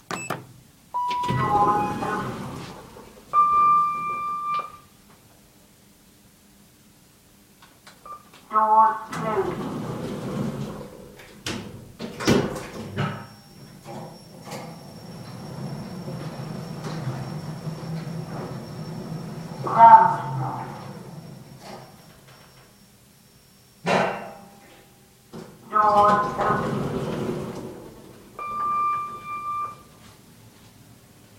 描述：进入电梯和电梯运行时的声音
标签： 声音 进入 升降机 电梯
声道立体声